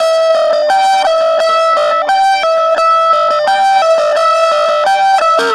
Track 02 - Guitar Lick 07.wav